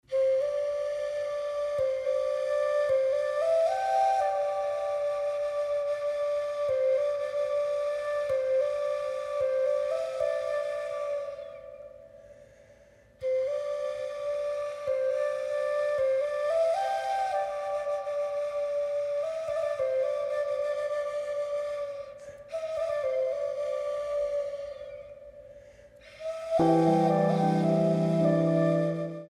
six beats